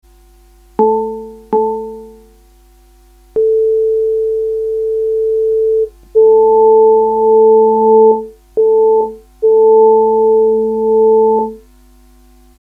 AVR Orgel Eigenbau
orgelrohr8-testklang2.mp3